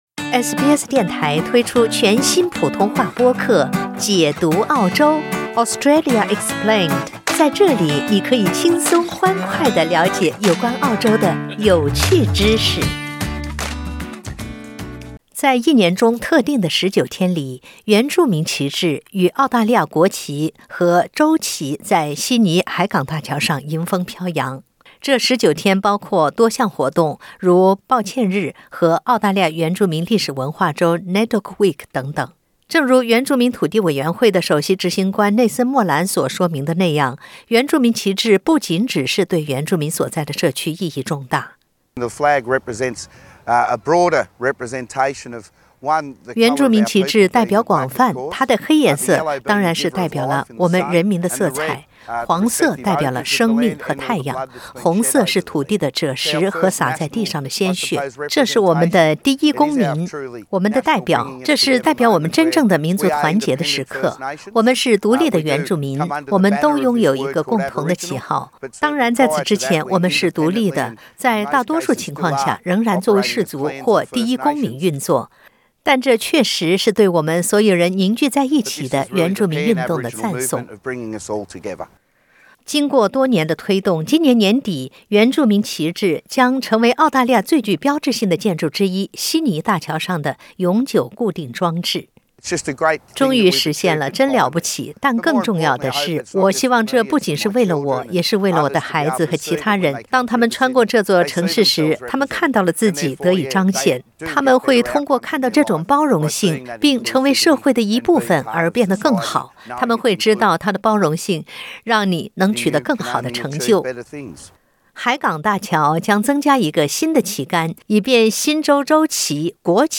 新南威尔士州的纳税人将支付超过 2000 万元，以使原住民旗帜在今年年底将在悉尼海港大桥上空飘扬。 新州州长佩罗提为这笔开支做出辩护，称这是为国家统一所付出的一个“小代价”。（点击上图收听报道）